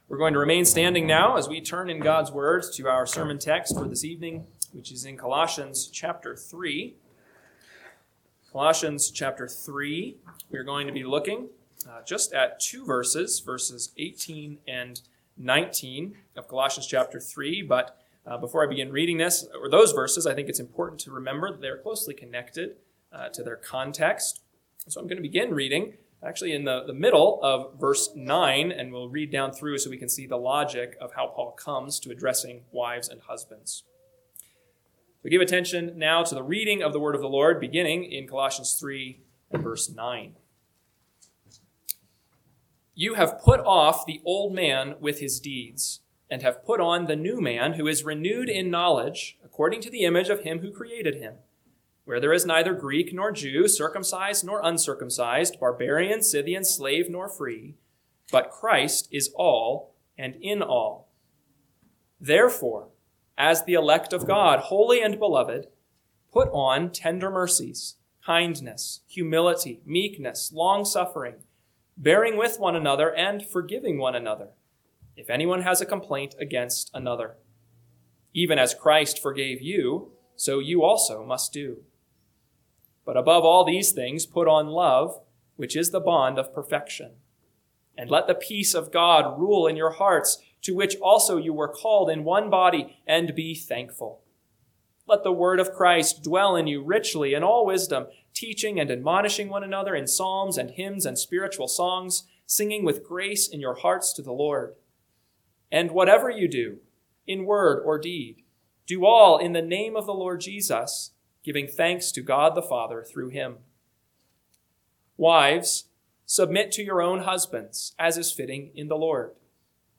PM Sermon – 4/12/2026 – Colossians 3:18-19 – Northwoods Sermons